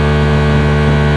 Engines
1 channel